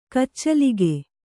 ♪ kaccalige